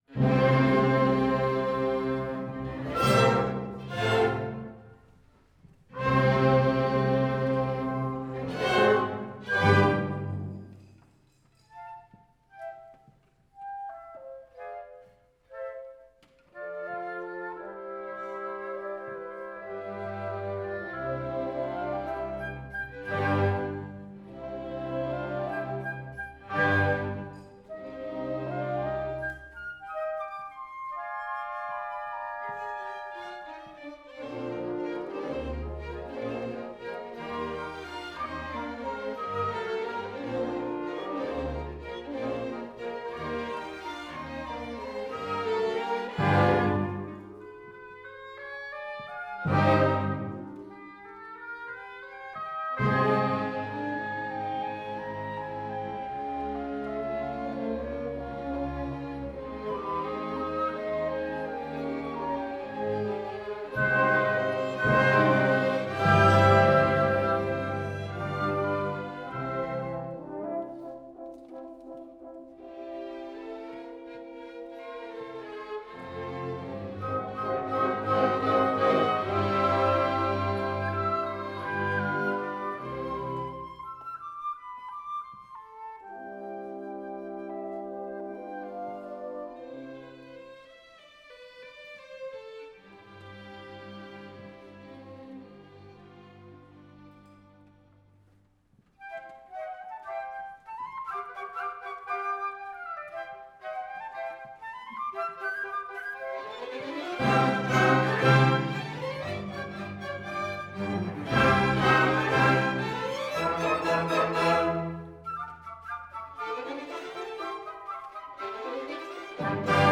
Live-Mitschnitt: 12.10.2025, Dreieinigkeitskirche, Berlin - Neukölln